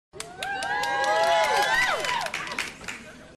Disney Clapping Sound Button - Free Download & Play